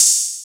SOUTHSIDE_open_hihat_ghetto.wav